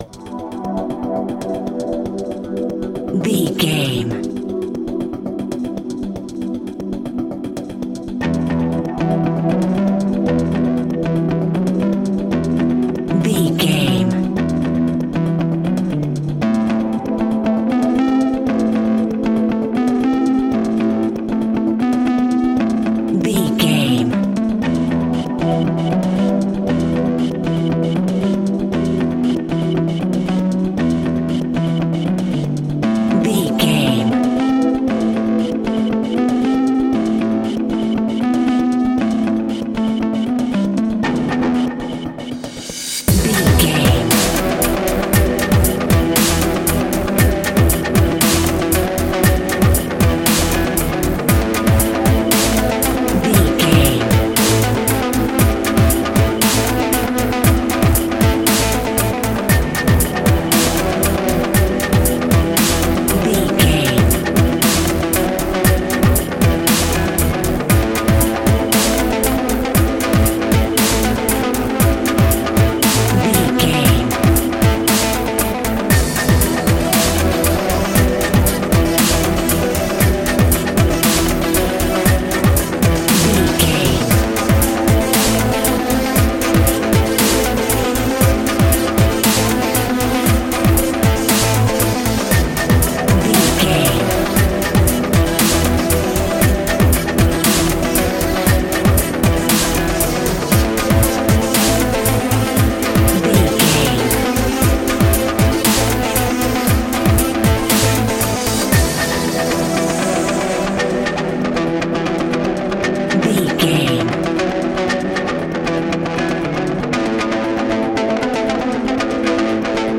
Aeolian/Minor
Fast
uplifting
futuristic
hypnotic
industrial
dreamy
smooth
drum machine
synthesiser
electric guitar
breakbeat
energetic
synth leads
synth bass